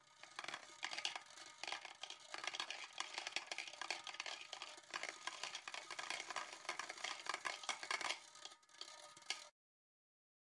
Descarga de Sonidos mp3 Gratis: canica 3.
canica-3-.mp3